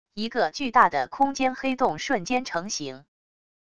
一个巨大的空间黑洞瞬间成型wav音频